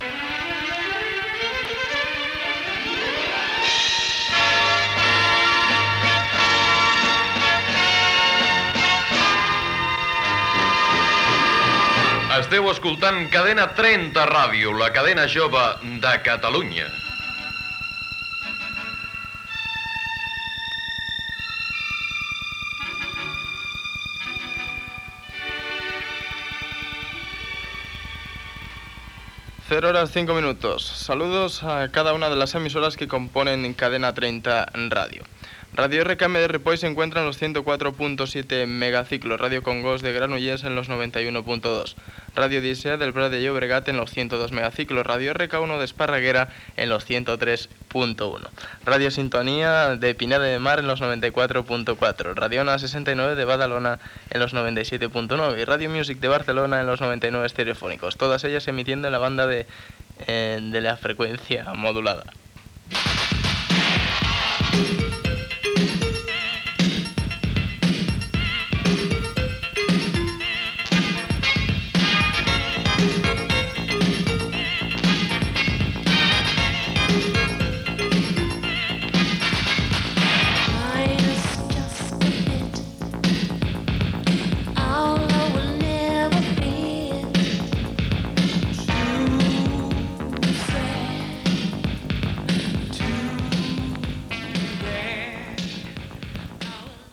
Indicatiu, emissores que formen part de la cadena i localitats, música.